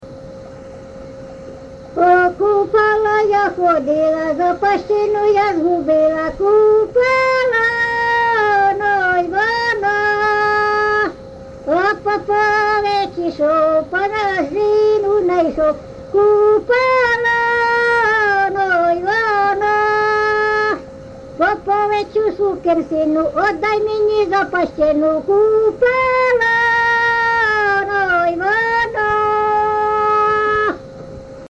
ЖанрКупальські
Місце записус. Ярмолинці, Роменський район, Сумська обл., Україна, Слобожанщина